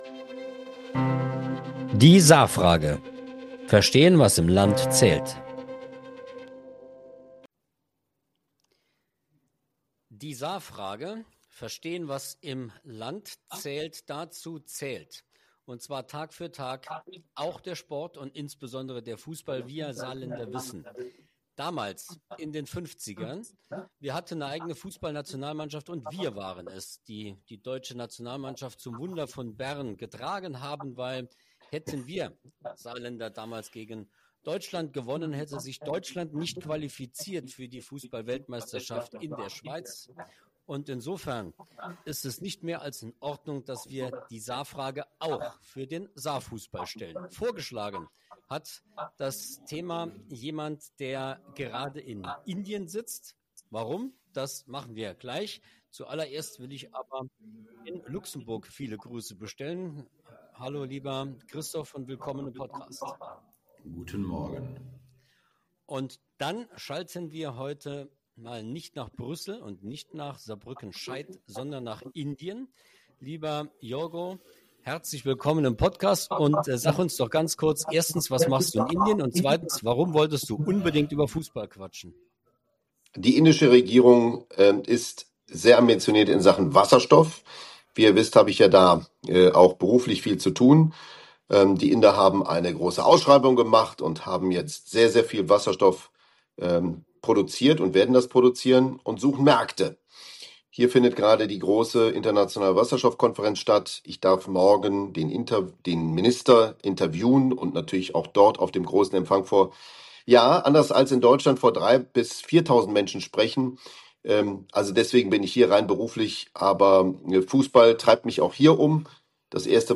Warum Elversberg mit Strategie und Ruhe Erfolge feiert – und Saarbrücken im eigenen Erwartungsdruck kämpft. Ein Gespräch über Führung, Identität und Stolz im kleinsten Flächenland Deutschlands.